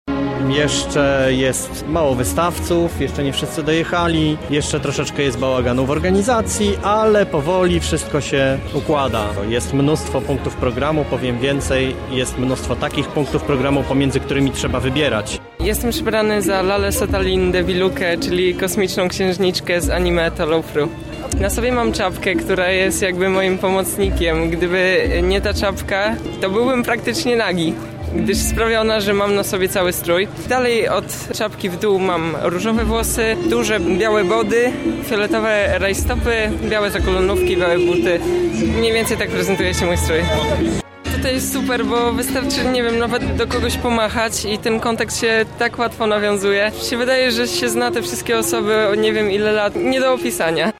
Najstarszy polski konwent fanów fantasy zawitał do Lublina! Ruszył Polcon 2017.